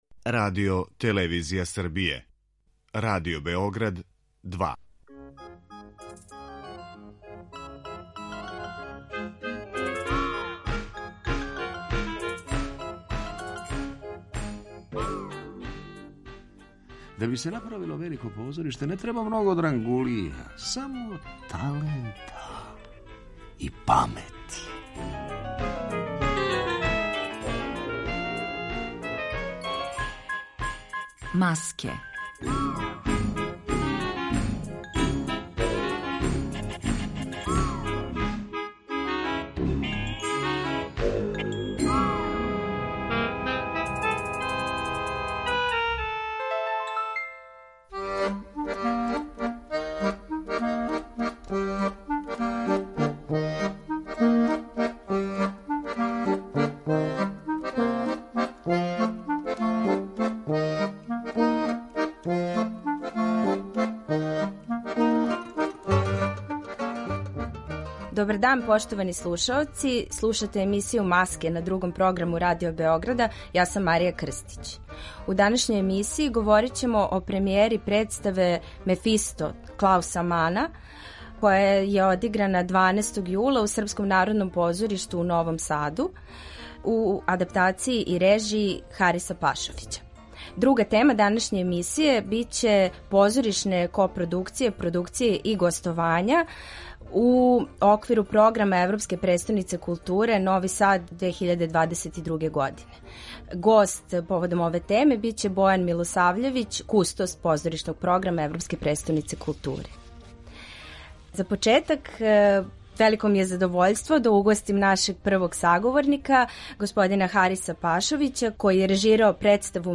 У данашњој емисији слушаћете разговор са Харисом Пашовићем , редитељем представе „Мефисто" и аутором адаптације истоименог романа Клауса Мана, поводом премијере у Српском народном позоришту 12. јула.